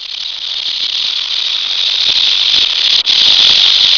rattle.wav